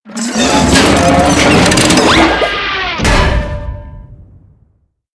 Converted sound effects
CHQ_VP_big_death.ogg